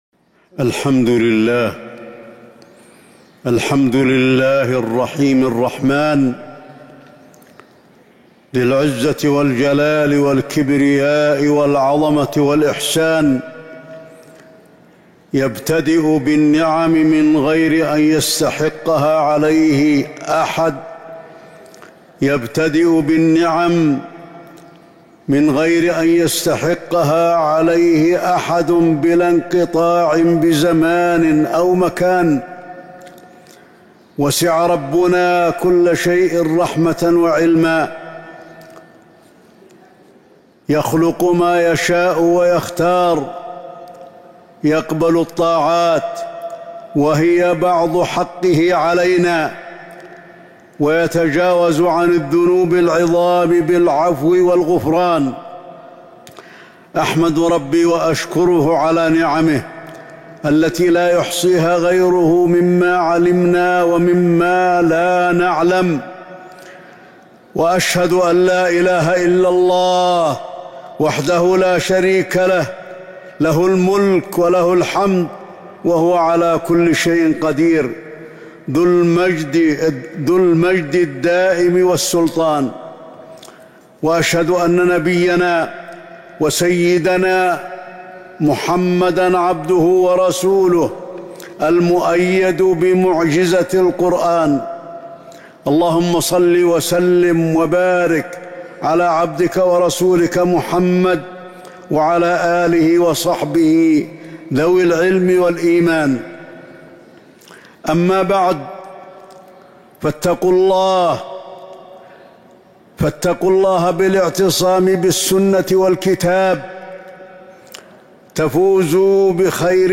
خطبة الجمعة 9 ذو الحجة 1443هـ | Khutbah Jumu’ah 8-7-2022 > خطب الحرم النبوي عام 1443 🕌 > خطب الحرم النبوي 🕌 > المزيد - تلاوات الحرمين